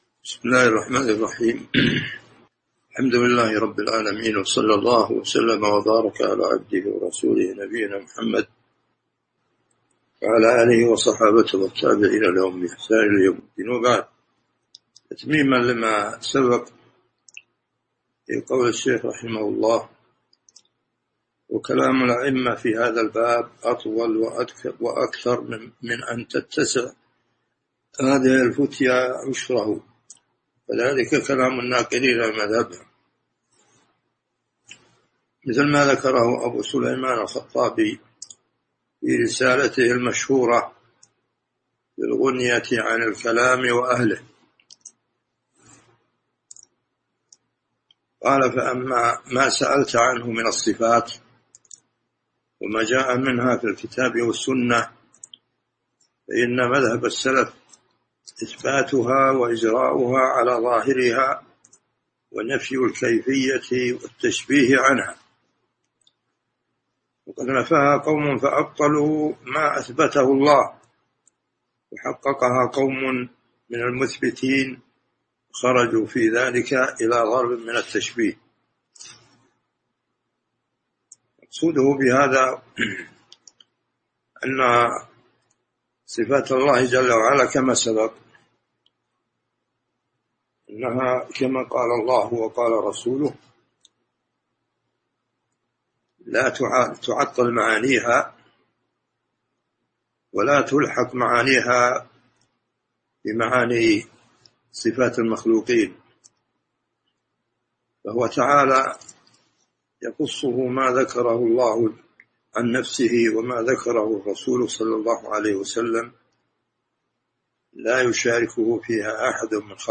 تاريخ النشر ٦ ذو القعدة ١٤٤٢ هـ المكان: المسجد النبوي الشيخ